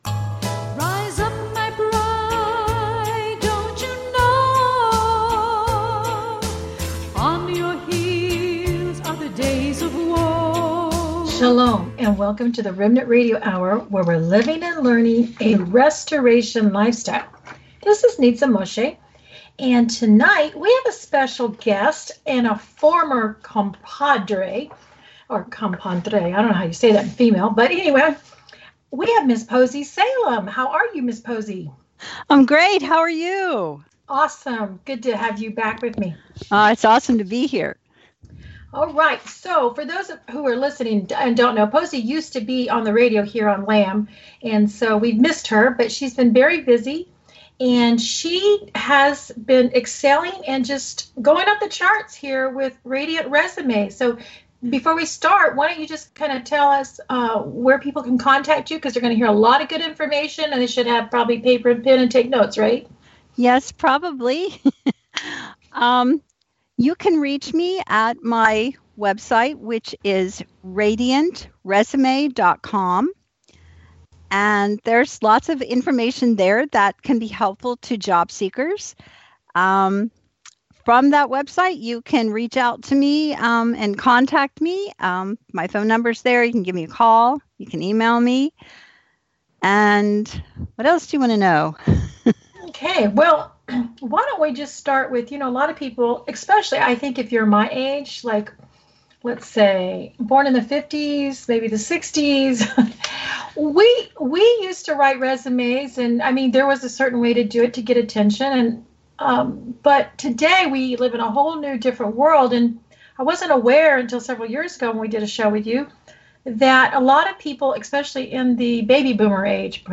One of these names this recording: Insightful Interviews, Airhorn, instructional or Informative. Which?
Insightful Interviews